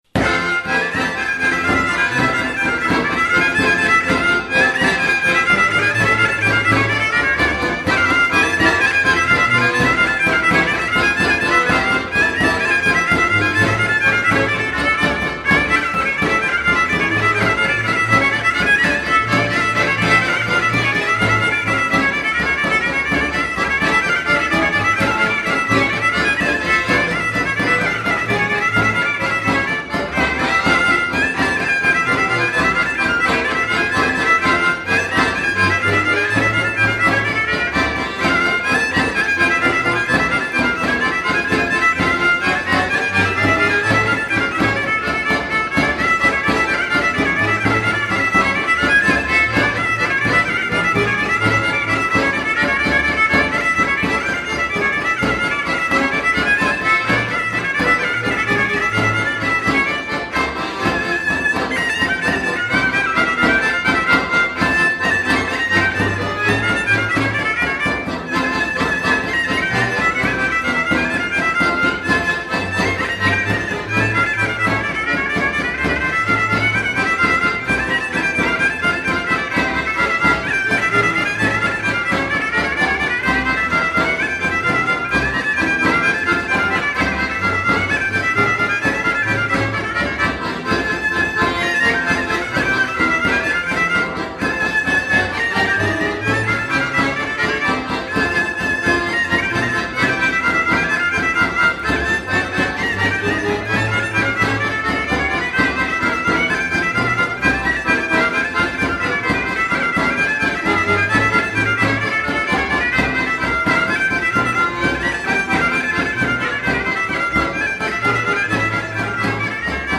vioară cu goarnă